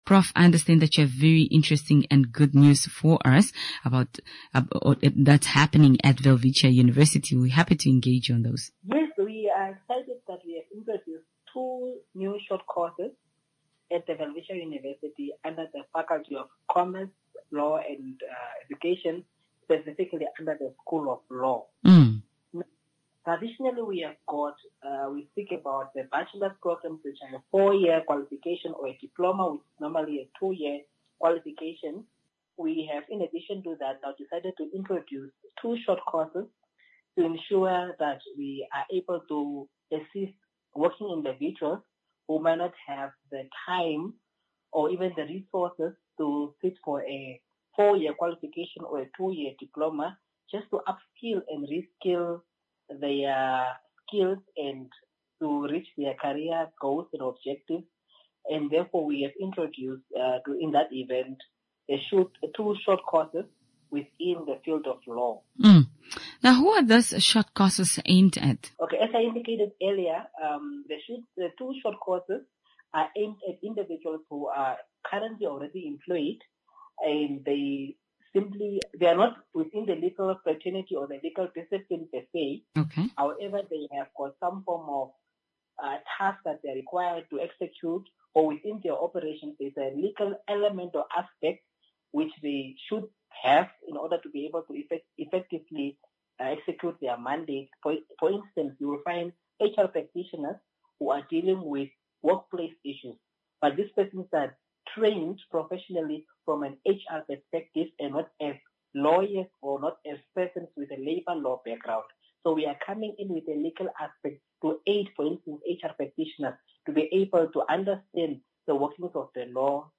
INTERVIEW WITH WELWITCHIA (5 FEBRUARY 2026)